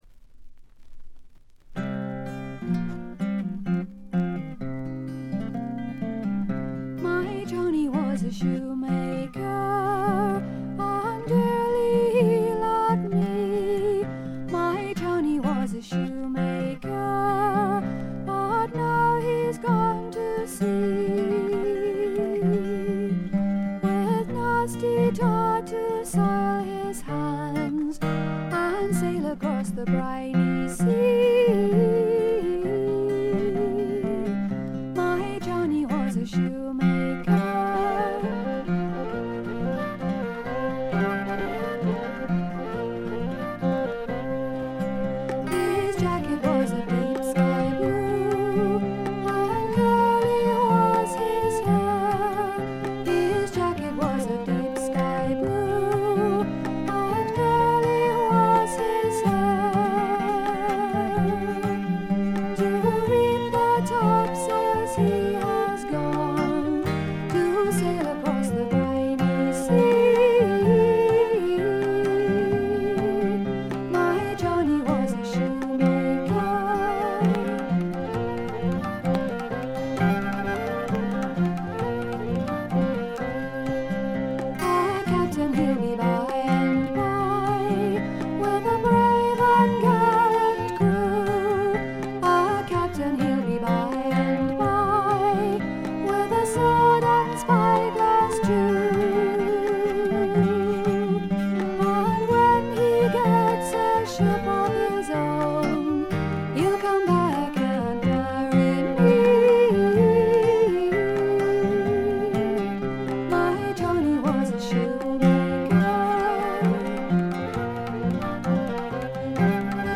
バックグラウンドノイズや軽微なチリプチは普通レベルで出ますが特筆するようなノイズはありません。
また専任のタブラ奏者がいるのも驚きで、全編に鳴り響くタブラの音色が得も言われぬ独特の味わいを醸しだしています。
試聴曲は現品からの取り込み音源です。